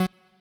left-synth_chord_last09.ogg